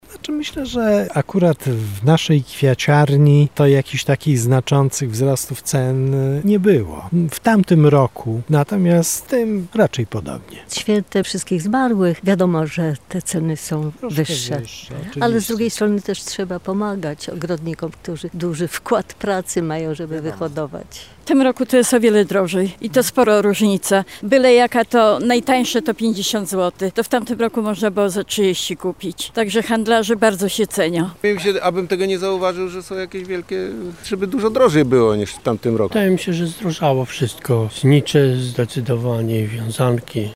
Postanowiliśmy więc zapytać mieszkańców Lublina, jak oni odczuwają te zmiany.:
SONDA